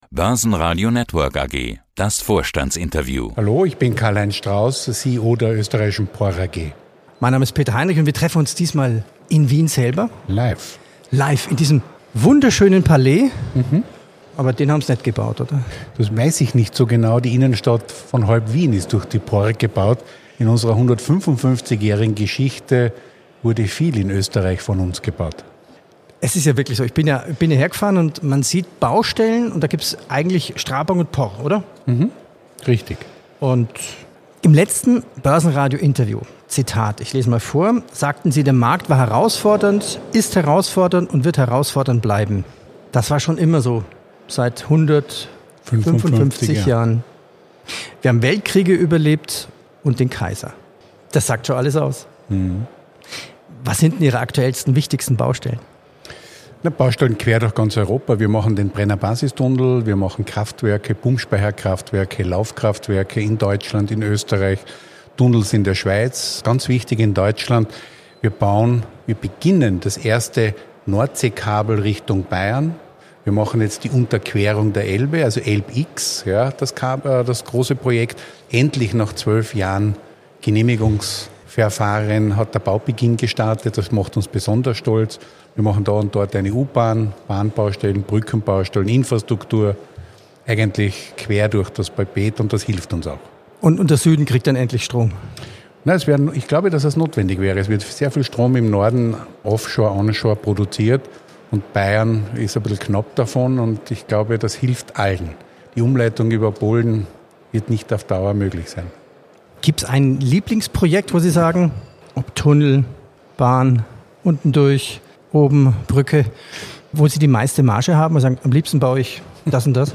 beim Wiener Börse Preis